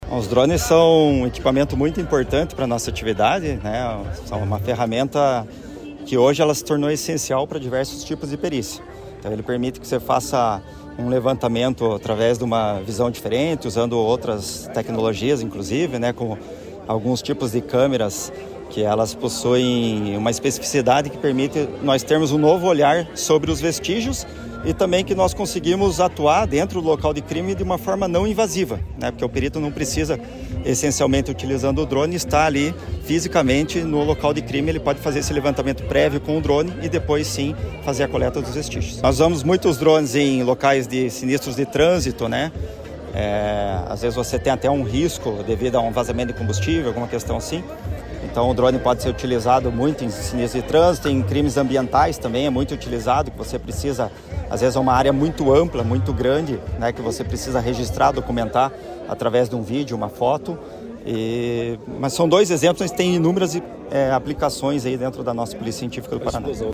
Sonora do diretor-geral da Polícia Científica, Ciro José Cardoso Pimenta, sobre a entrega de 243 drones para monitoramento aéreo das forças de segurança do Paraná